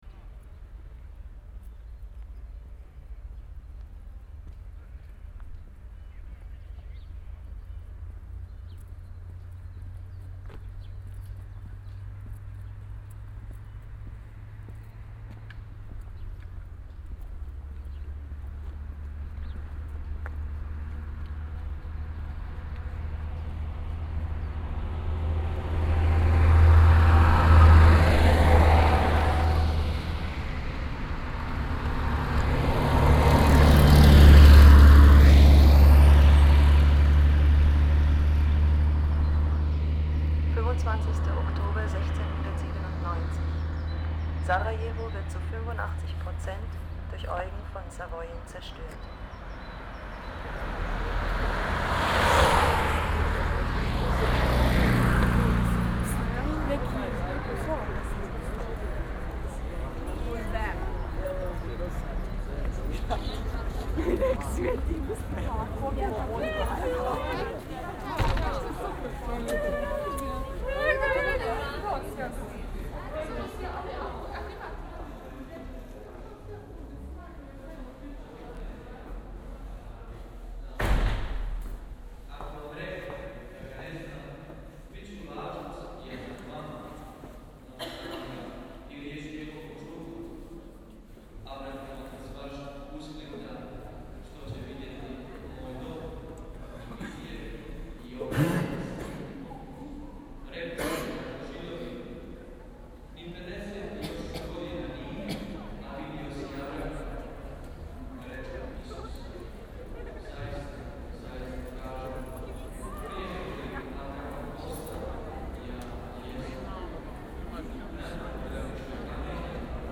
Soundscape compositions of fieldrecordings and texts